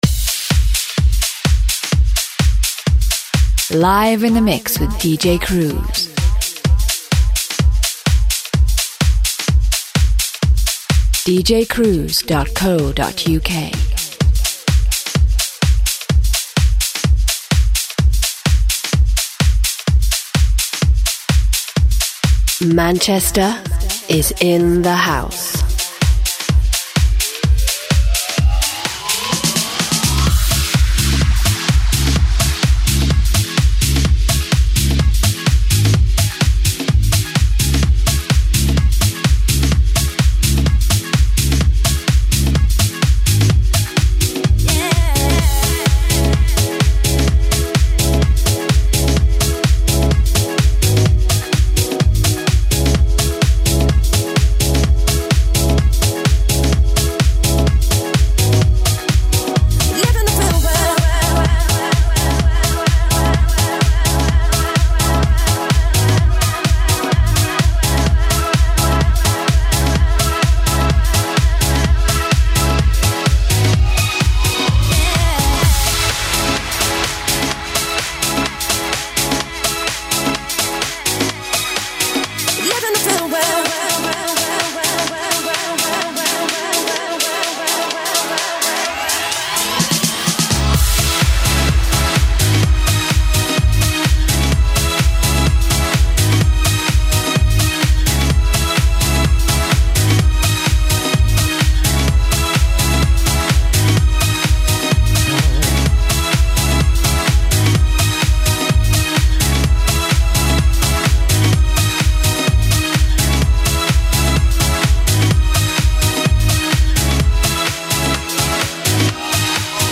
A funky house music mix